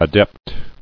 [a·dept]